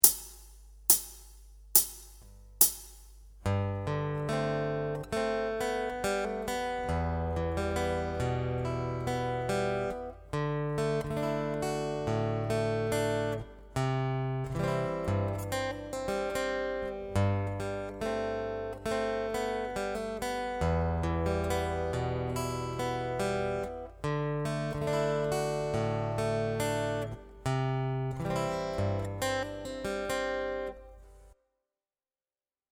However, there are countless ways to combine any two or more things together, so here is an example of doing exactly that with our chord progression.
Acoustic Rhythm Guitar Chord Embellishment 4